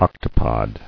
[oc·to·pod]